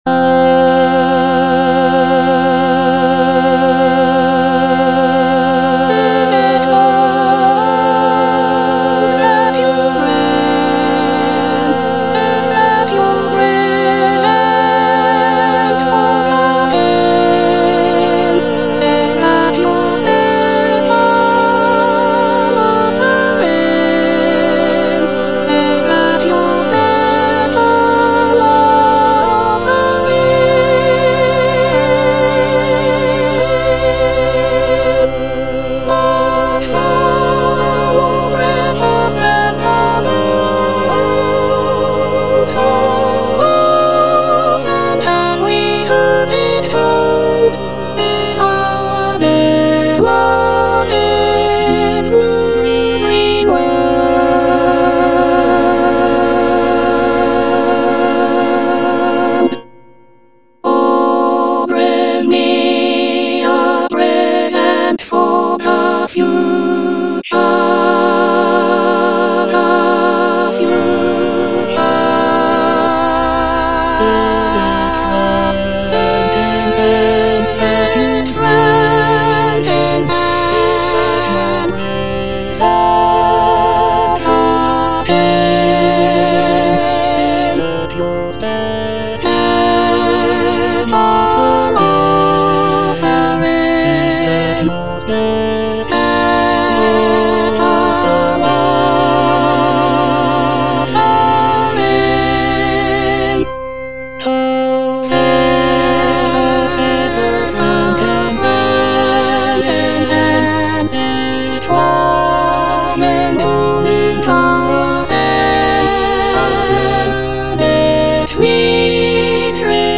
Soprano II (Emphasised voice and other voices)
is a choral work